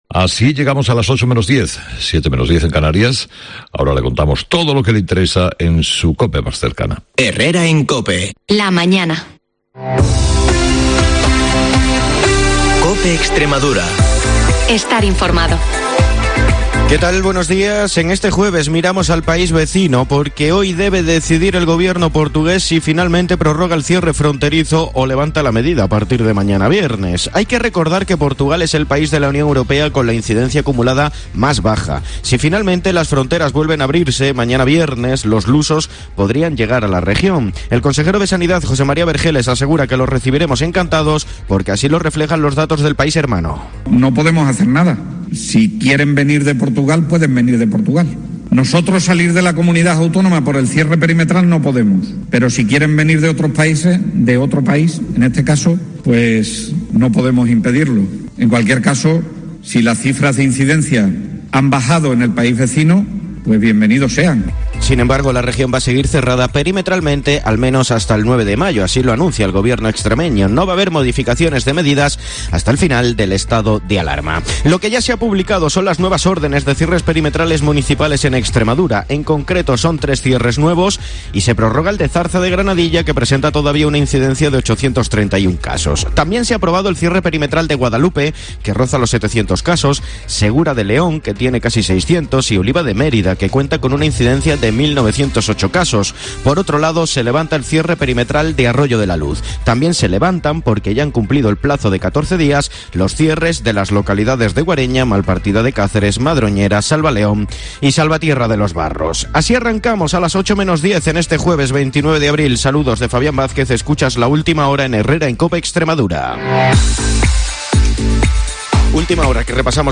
el informativo líder de la radio en Extremadura